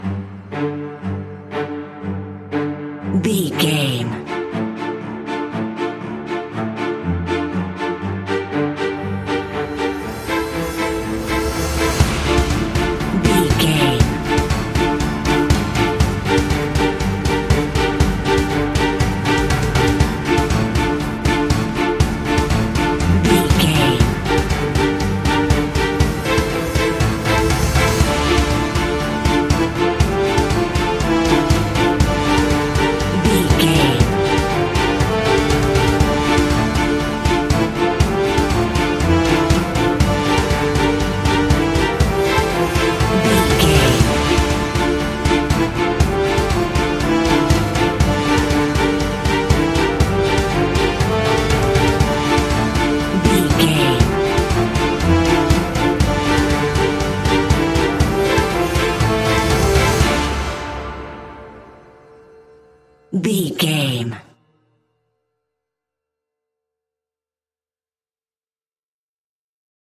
In-crescendo
Thriller
Aeolian/Minor
ominous
dark
haunting
eerie
creepy
horror music
Horror Pads
horror piano
Horror Synths